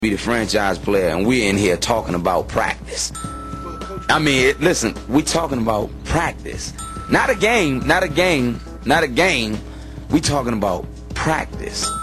Play Ai Practice Rant - SoundBoardGuy
allen-iverson-talks-about-practice-3.mp3